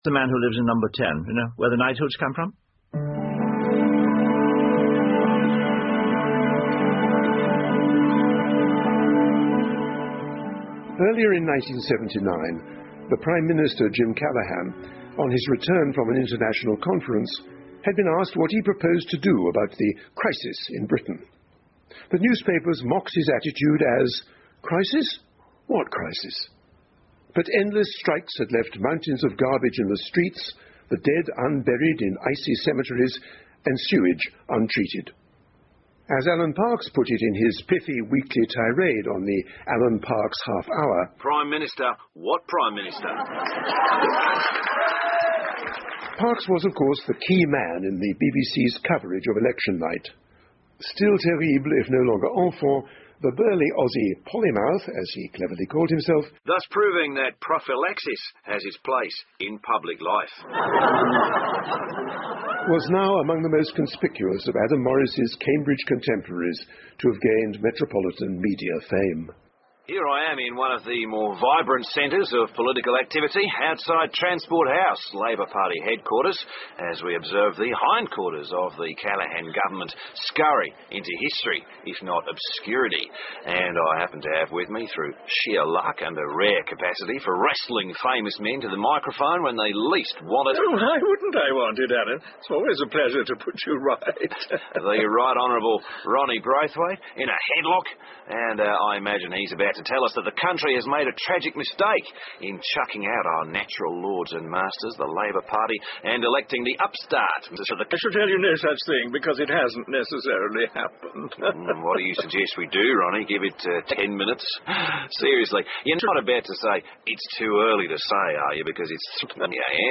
英文广播剧在线听 Fame and Fortune - 3 听力文件下载—在线英语听力室